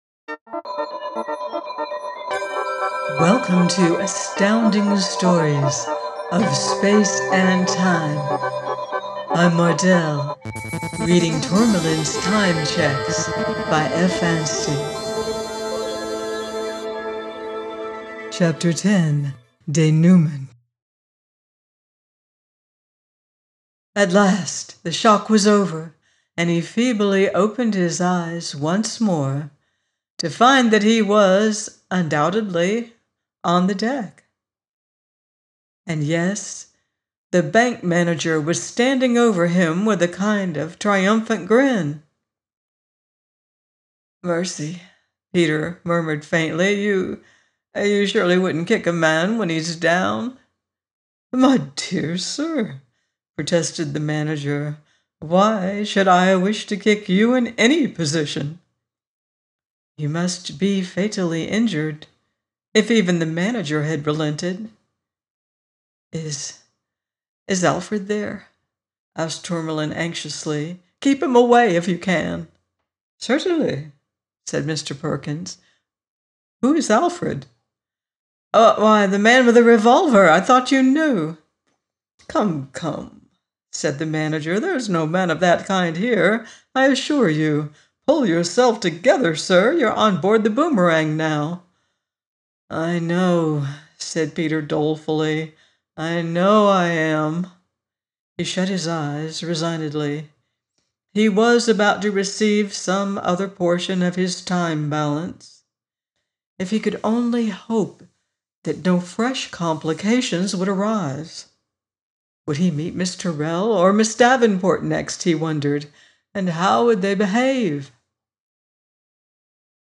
Tourmalin’s Time Cheques – by F. Anstey - audiobook